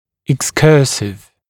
[ɪks’kɜːsɪv] [eks-][икс’кё:сив] [экс-]отклоняющийся; блуждающий (об органе)